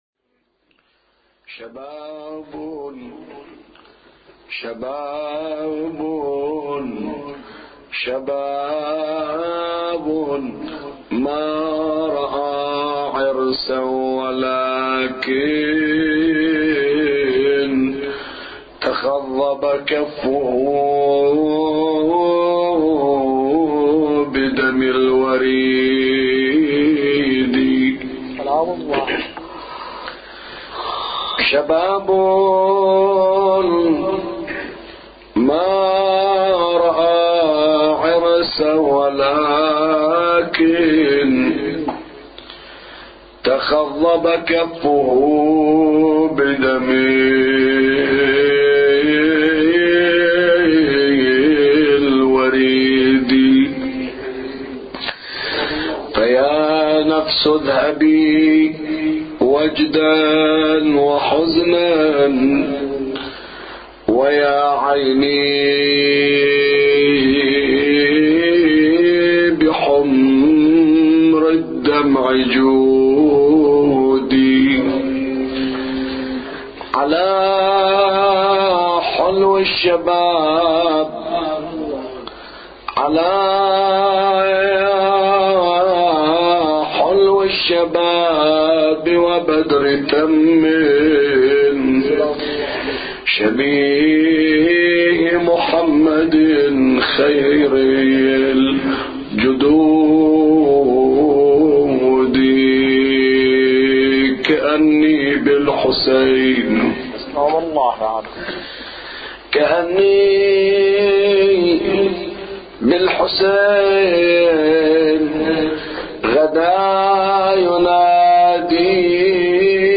أبيات حسينية – ليلة التاسع من شهر محرم